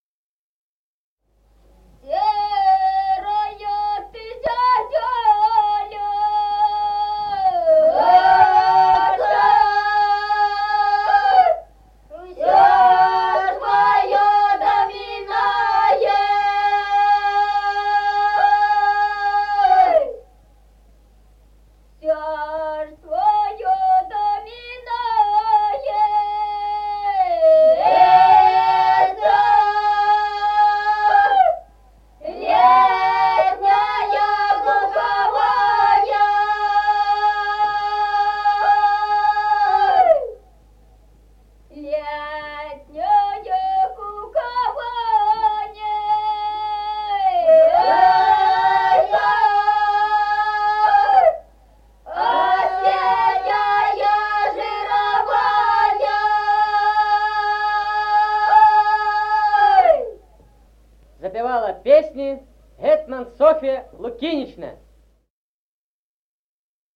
Народные песни Стародубского района «Серая ты зязюлечка», жнивная.
(подголосник)
(запев).
с. Мишковка.